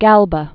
(gălbə, gôl-), Servius Sulpicius 3 BC-AD 69.